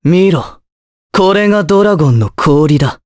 Pavel-Vox_Skill5_jp.wav